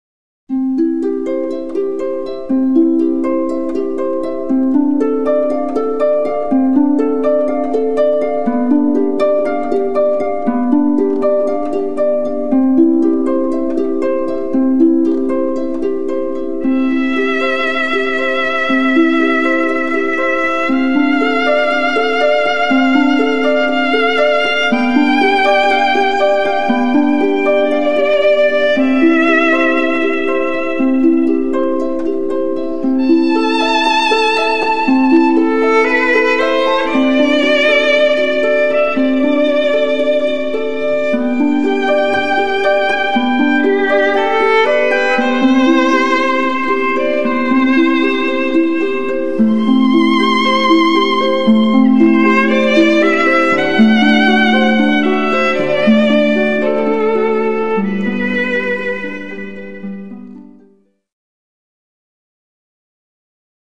Genre: Blues.
cd38_harp.mp3